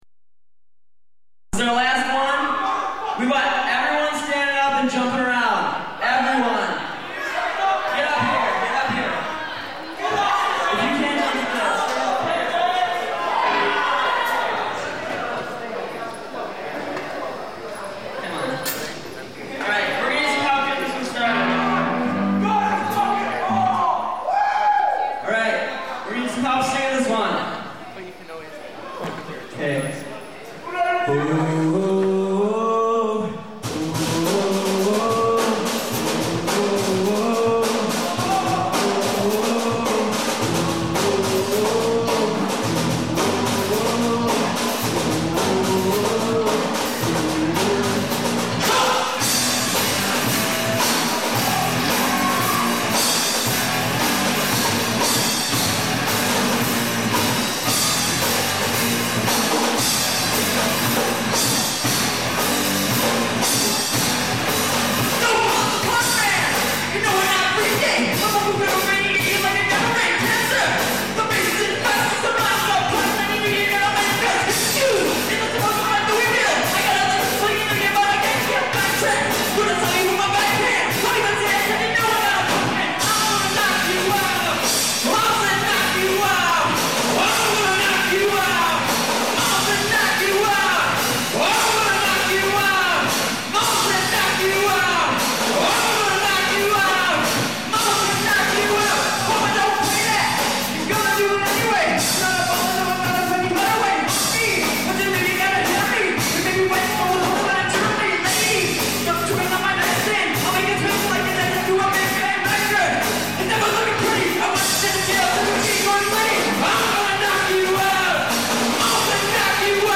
(Elk Rapids Town Hall 4/29/00)